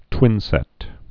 (twĭnsĕt)